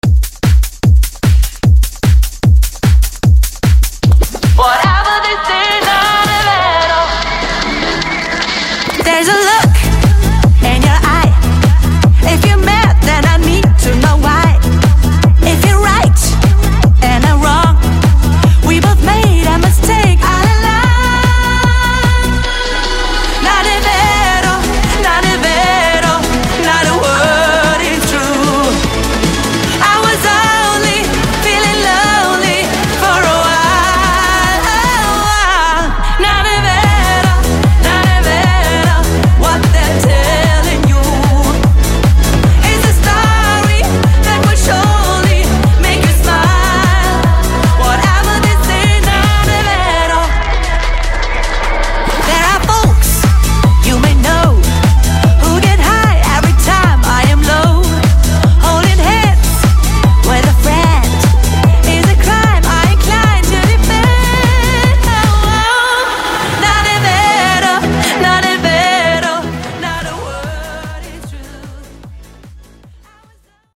Genre: DANCE
Clean BPM: 138 Time